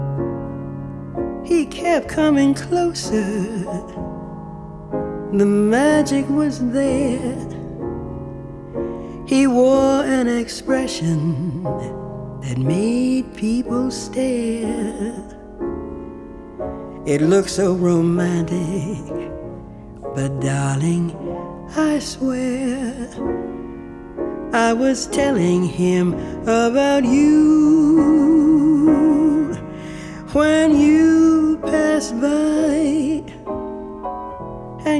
Жанр: Джаз